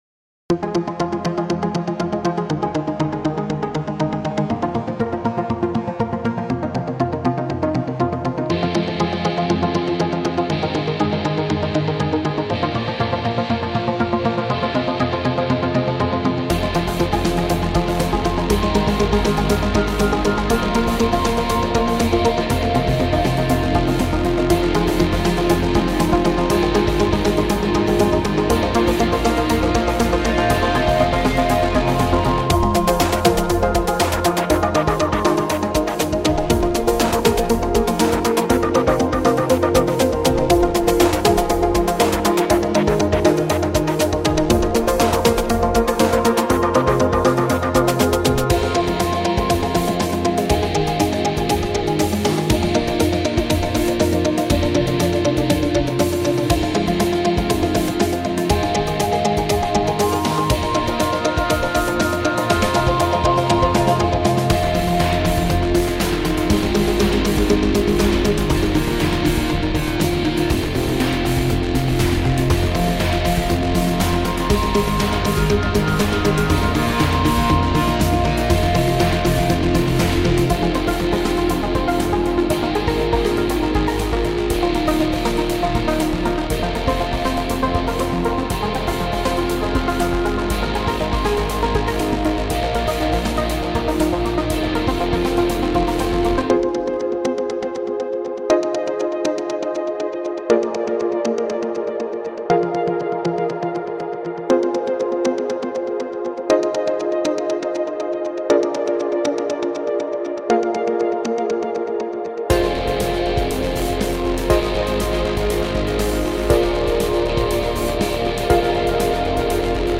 Rhythmic track for sports and strategy.